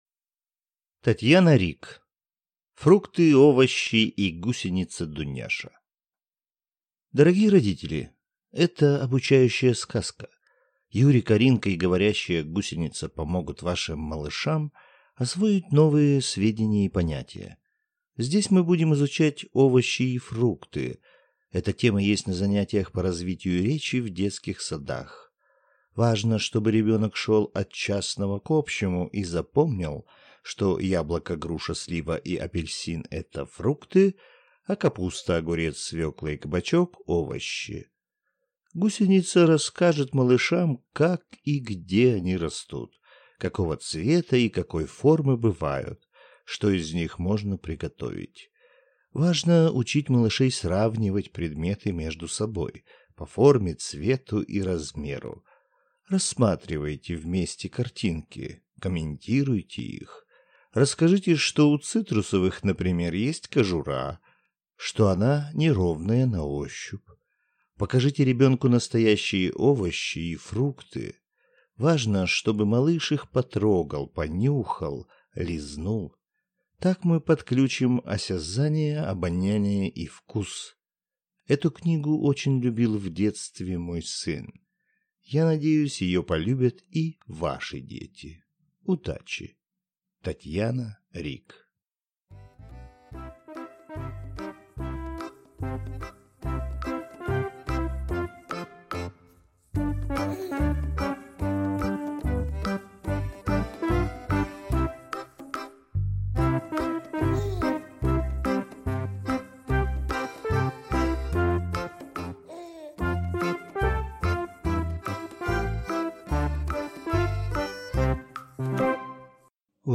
Аудиокнига Фрукты, овощи и гусеница Дуняша | Библиотека аудиокниг